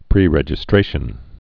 (prērĕj-ĭ-strāshən)